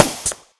dartShoot.ogg